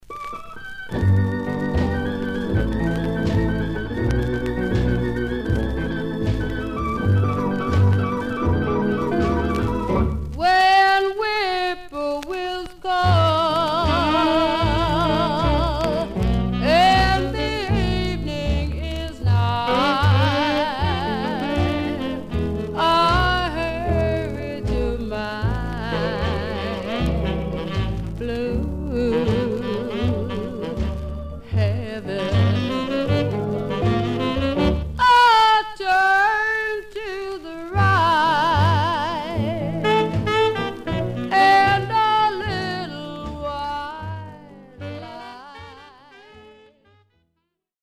Stereo/mono Mono
Jazz